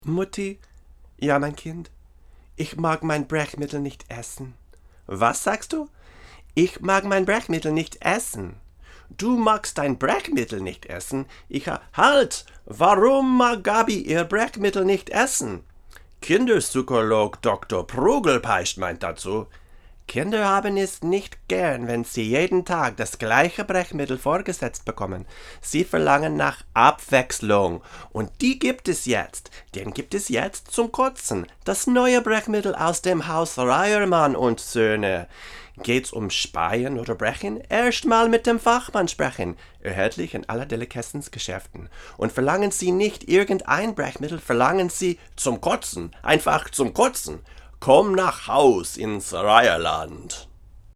Here are some quick, 1-take MP3 sound files showing how each pattern sounds through a Presonus ADL 600 preamp into a Rosetta 200 A/D converter.
Voice English / German / Latin